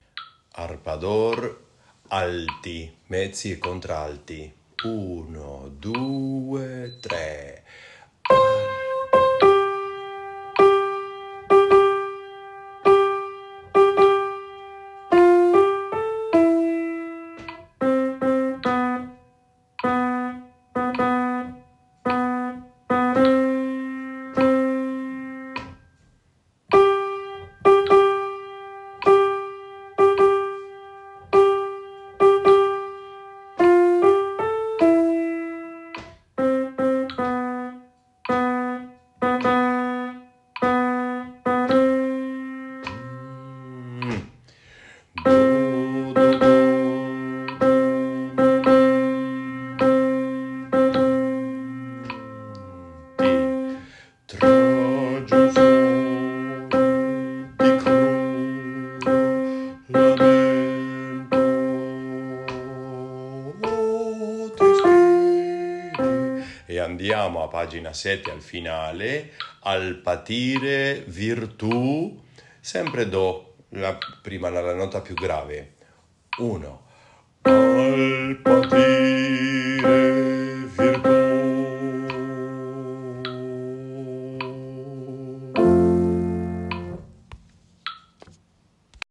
A.A. 24/25 Canto Corale
Arpa d’Or ALTO
Arpa-dor-Verdi-ALTO.mp3